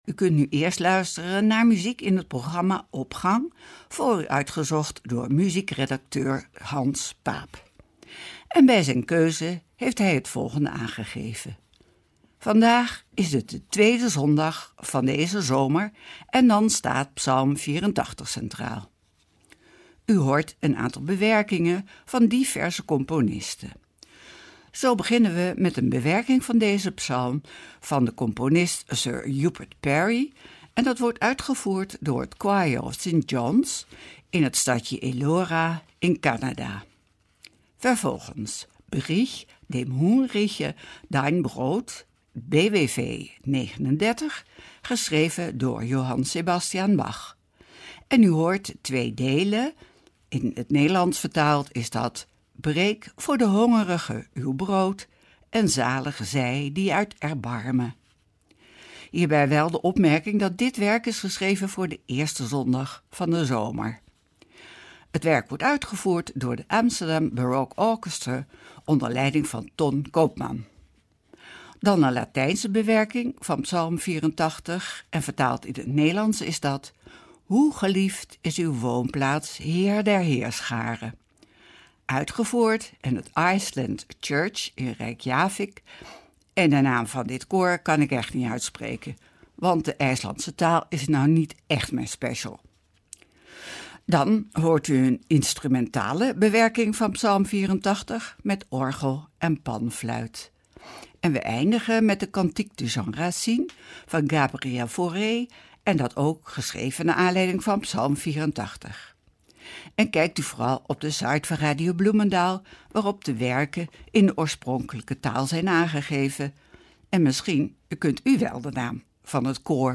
Opening van deze zondag met muziek, rechtstreeks vanuit onze studio.
orgel en panfluit